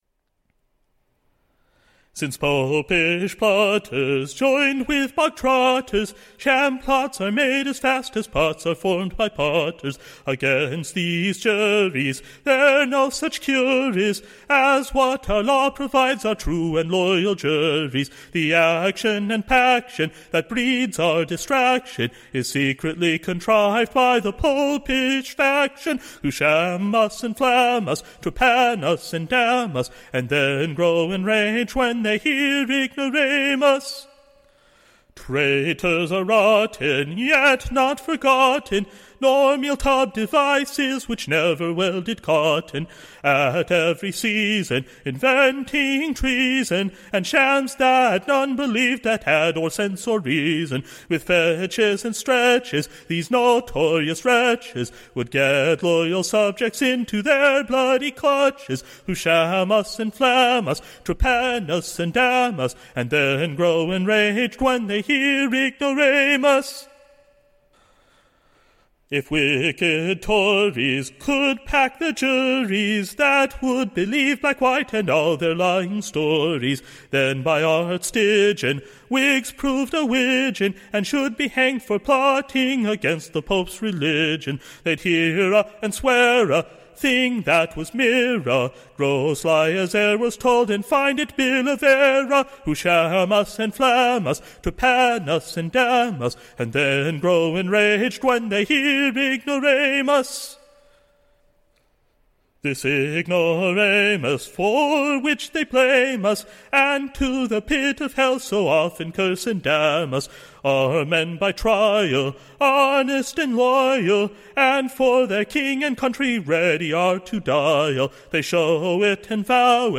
Recording Information Ballad Title A NEW / IGNORAMUS: / Being the second New SONG.